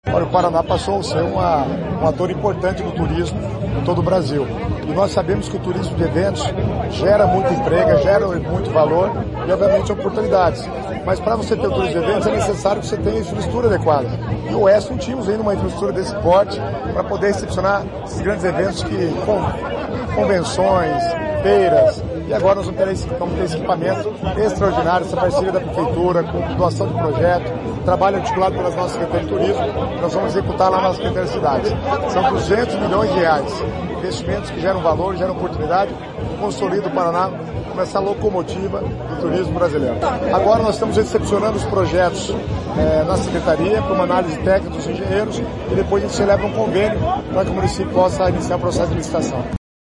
Sonora do secretário das Cidades, Guto Silva, sobre a construção do centro de convenções e eventos do Oeste